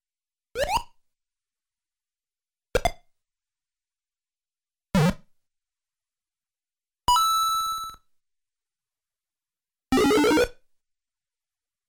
踩怪 踢龟壳 龟壳反弹（撞到头） 顶到金币 顶到蘑菇.mp3